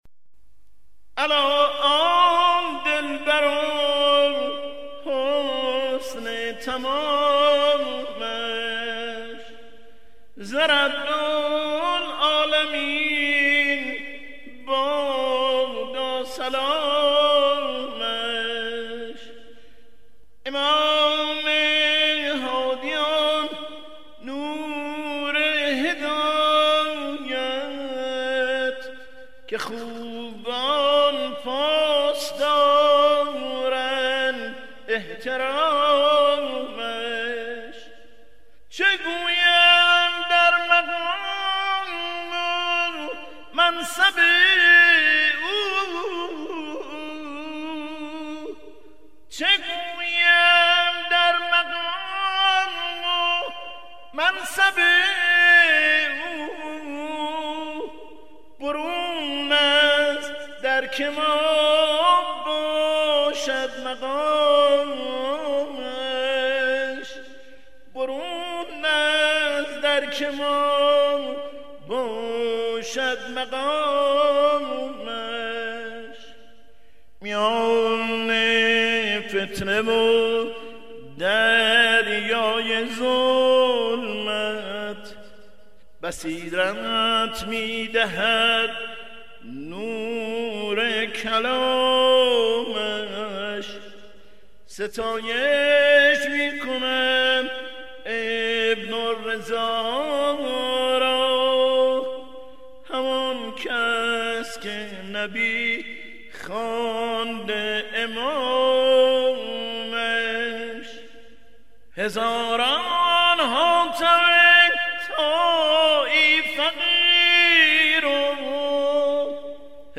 صوتی | مدح امام هادی علیه السلام